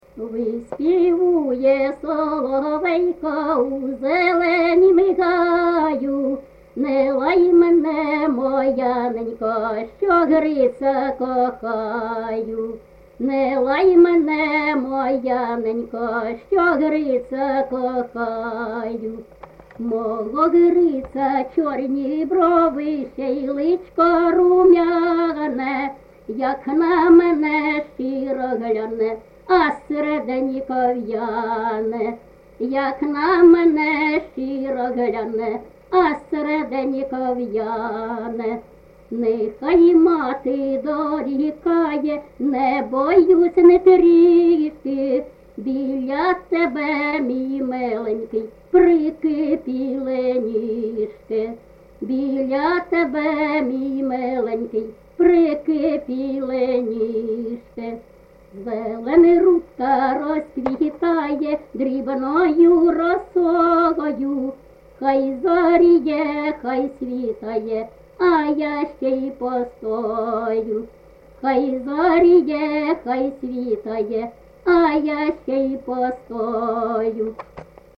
ЖанрПісні з особистого та родинного життя
Місце записус-ще Михайлівське, Сумський район, Сумська обл., Україна, Слобожанщина